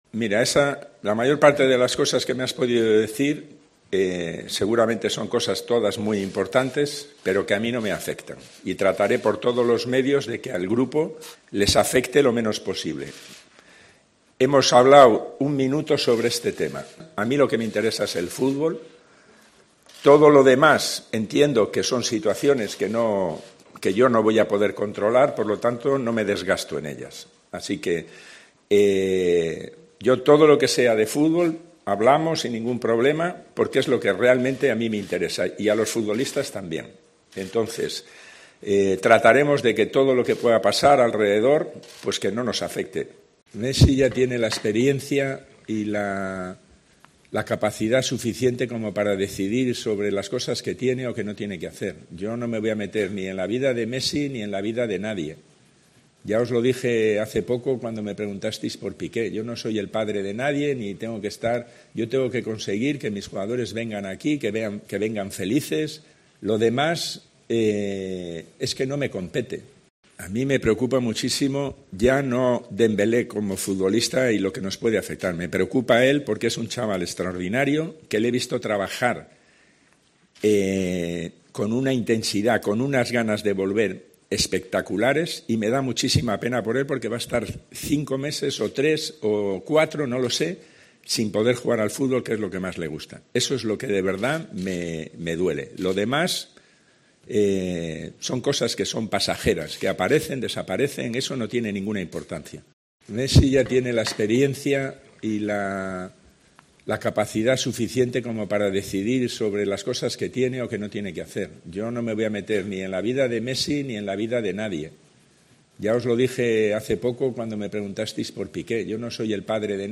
AUDIO: El entrenador del Barça habla sobre la crisis, Leo Messi, Dembélé y el posible fichaje del 9